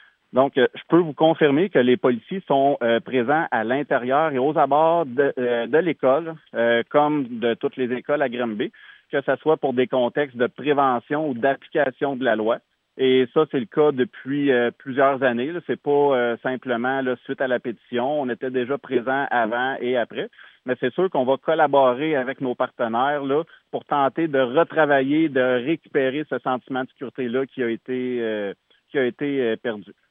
En entrevue avec le service de nouvelles de M105